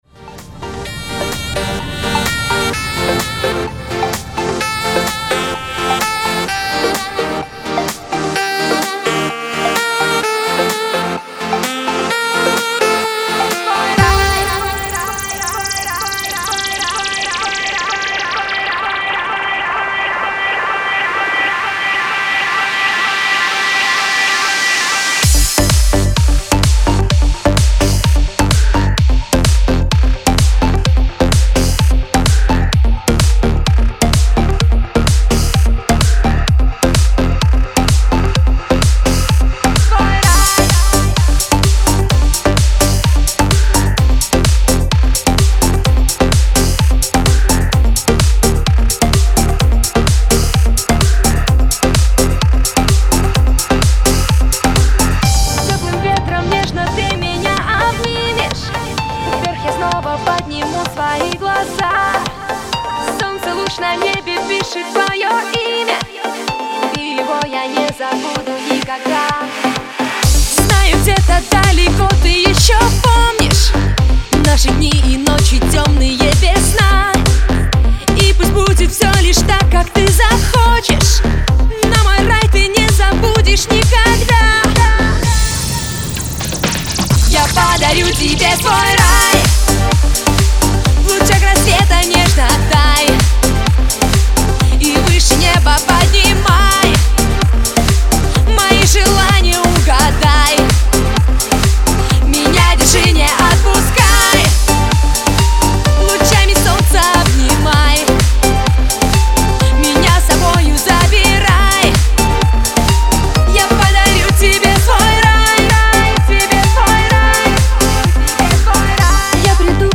Категория: Club - Mix